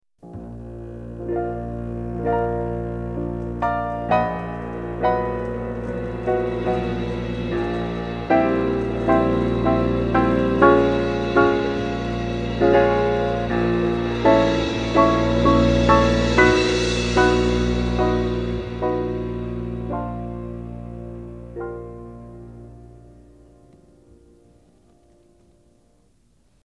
A warm, romantic ballad